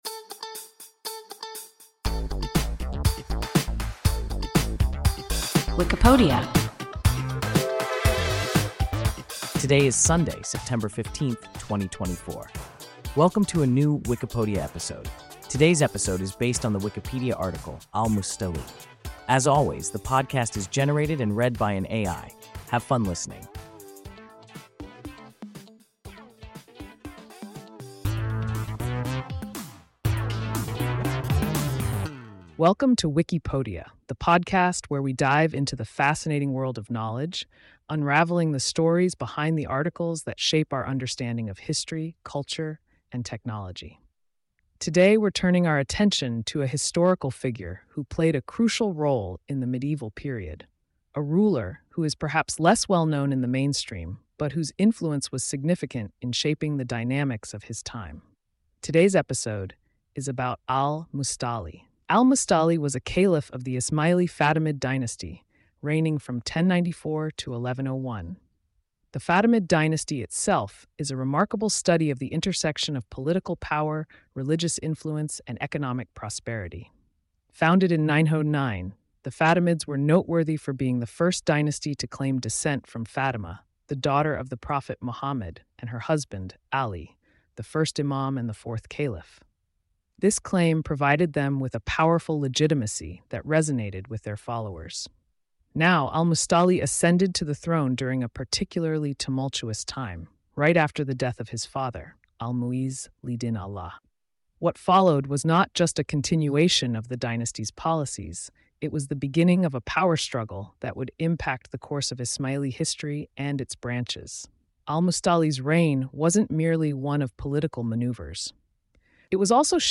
Al-Musta’li – WIKIPODIA – ein KI Podcast